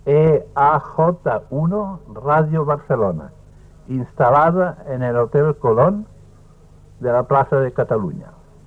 Recreació de la identificació de l'emissora, transmetent en període de proves des de l'Hotel Colón de la Plaça de Catalunya de Barcelona
Fragment extret de "SER 100" del web de la Cadena SER.